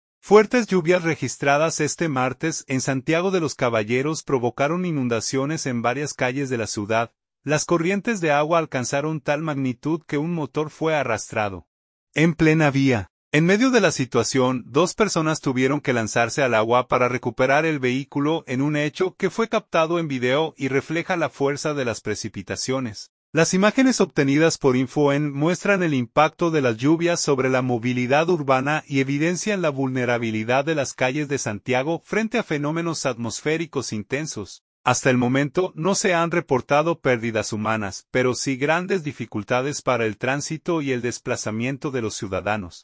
Las corrientes de agua alcanzaron tal magnitud que un motor fue arrastrado en plena vía. En medio de la situación, dos personas tuvieron que lanzarse al agua para recuperar el vehículo, en un hecho que fue captado en video y refleja la fuerza de las precipitaciones.